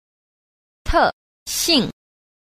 4. 特性 – tèxìng – đặc tính
Cách đọc: